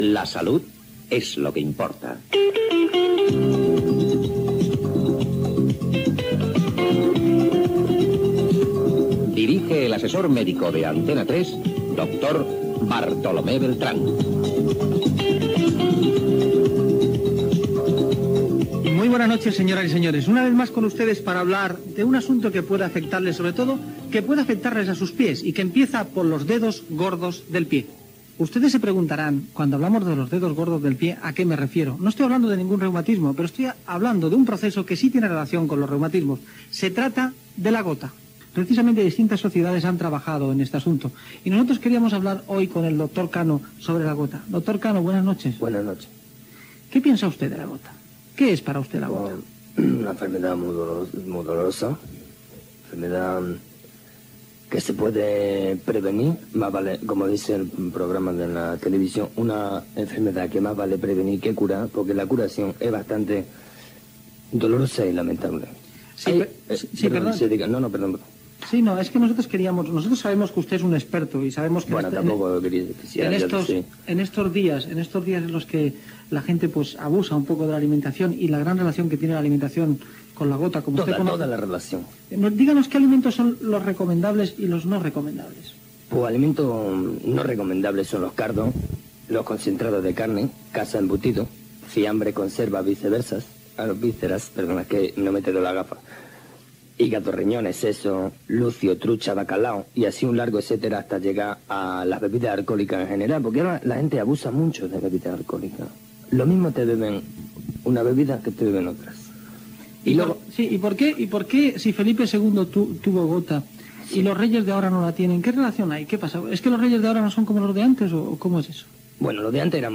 Careta del programa, presentació i entrevista amb un fictici doctor sobre la "gota". Amb la participació de Juan Luis Cano de Gomaespuma. Espai emès el dia dels Sants Innocents.
Divulgació